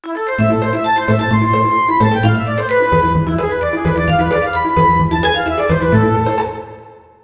Tonalidad de La menor. Ejemplo.
tristeza
triste
dramatismo
melodía
serio
severo
sintetizador
Sonidos: Música